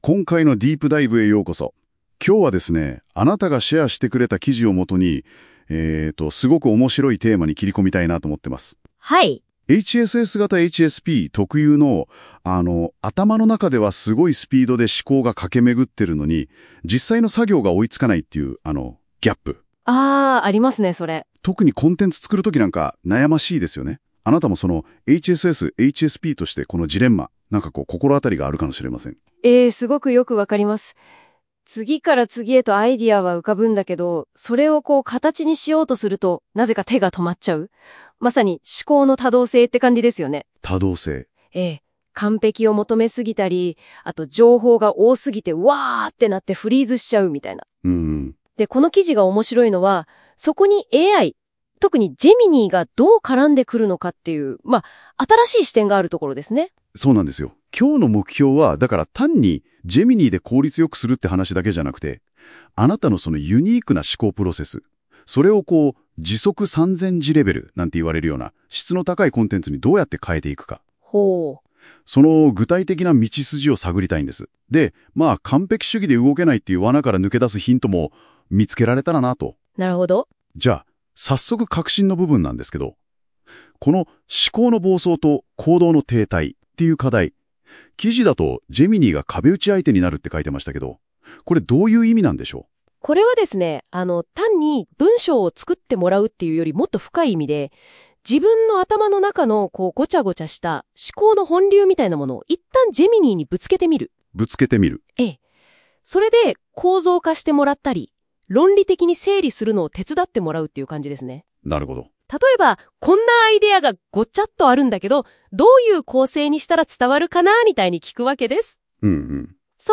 【音声解説】HSS型HSPの思考の暴走をGeminiが「知的資産」に変える！高速思考を活かすAI戦略と質問力